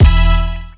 Kick10.wav